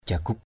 /ʥa-ɡ͡ɣuk/ (d.) quân xâm lược. invaders. jaguk gayup nao nagar jg~K gy~P _n< ngR quân xâm lược tháo chạy về nước.